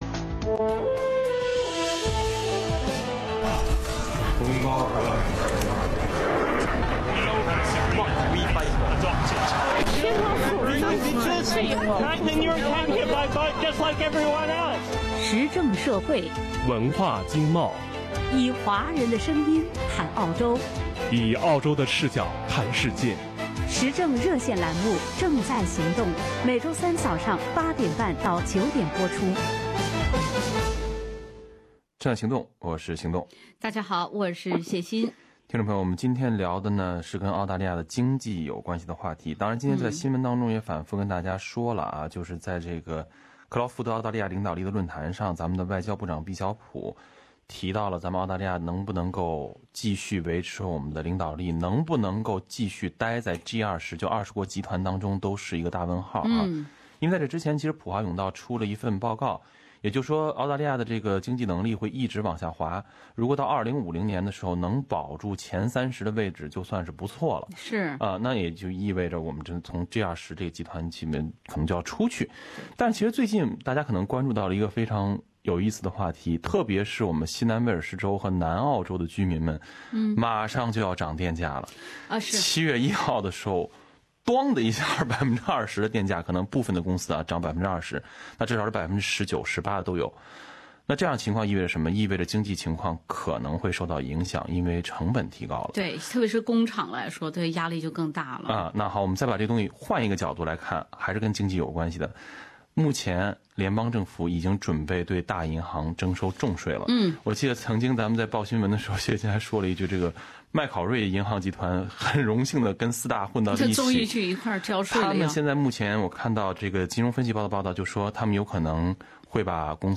听众讨论未来澳洲经济的挑战。